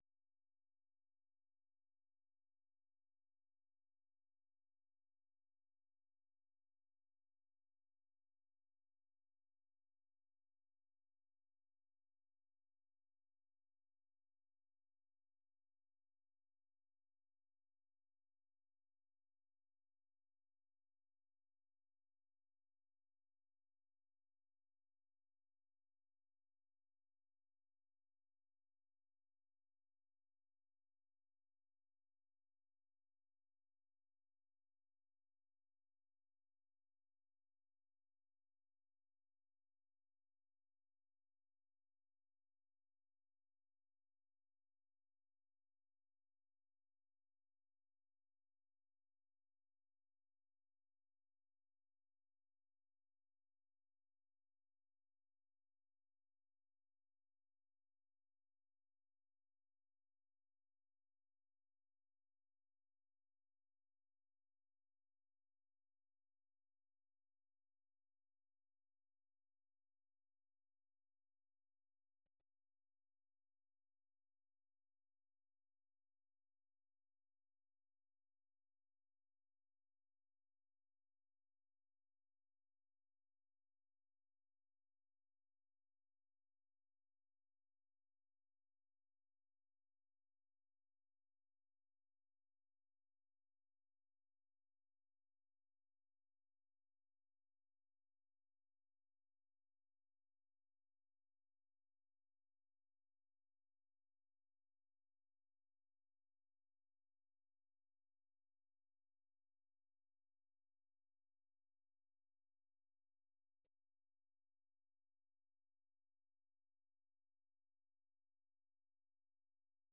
ဗွီအိုအေမြန်မာပိုင်းရဲ့ ညပိုင်း မြန်မာစံတော်ချိန် ၉ နာရီမှ ၁၀နာရီအထိ ရေဒီယိုအစီအစဉ်ကို ရေဒီယိုကနေ ထုတ်လွှင့်ချိန်နဲ့ တပြိုင်နက်ထဲမှာပဲ Facebook နဲ့ Youtube ကနေလည်း တိုက်ရိုက် ထုတ်လွှင့်ပေးနေပါတယ်။